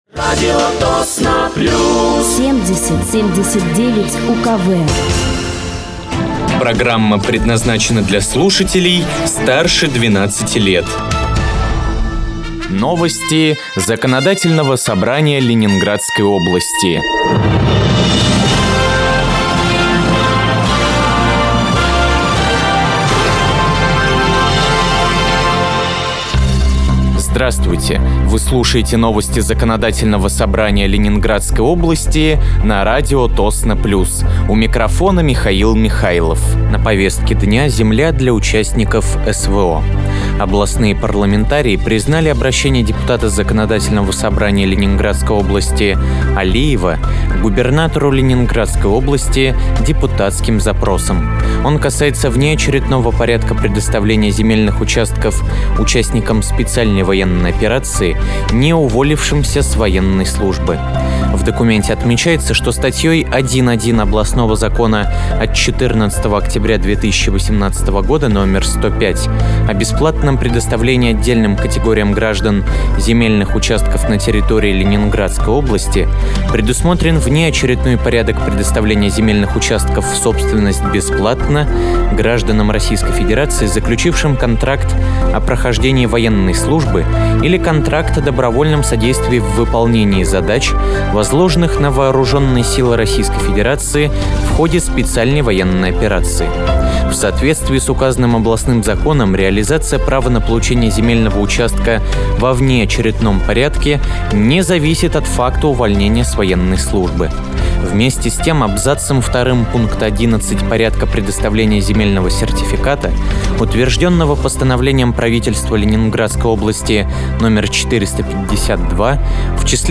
Вы слушаете новости Законодательного собрания Ленинградской области на радиоканале «Радио Тосно плюс».